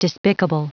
Prononciation du mot despicable en anglais (fichier audio)
Prononciation du mot : despicable